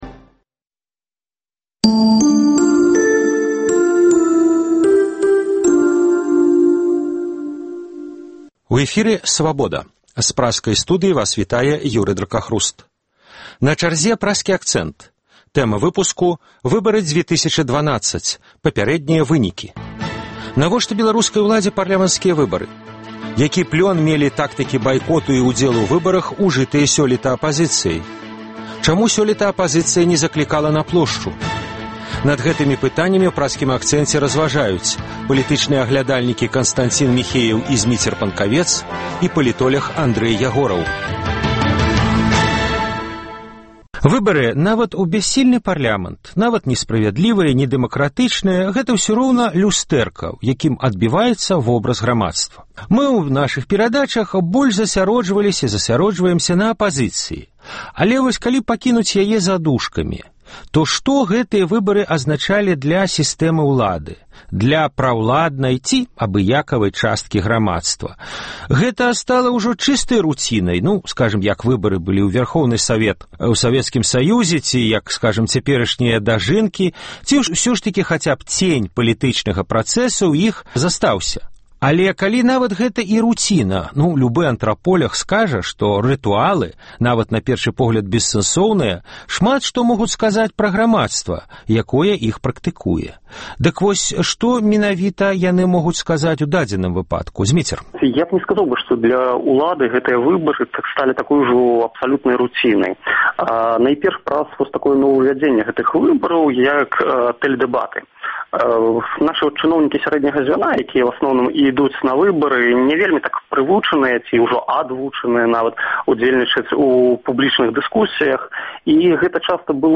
Над гэтымі пытаньнямі ў «Праскім акцэнце» разважаюць палітычныя аглядальнікі